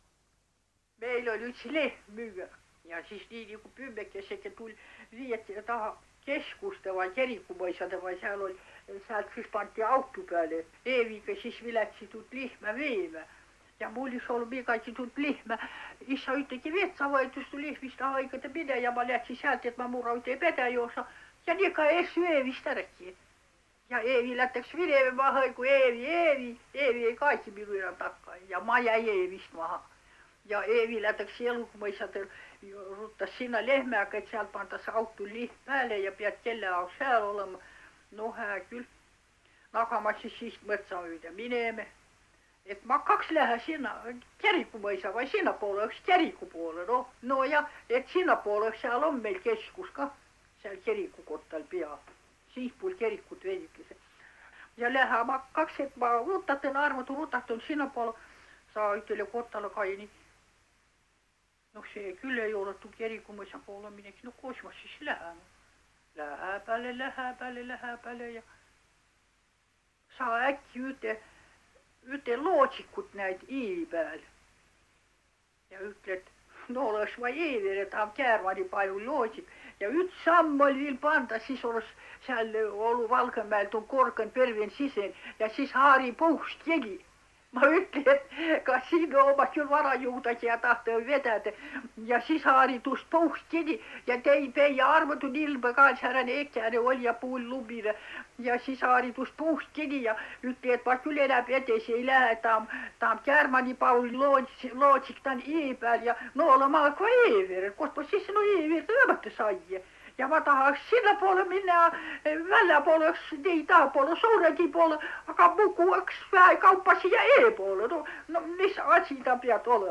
MurdekiikerVõru murreVHargla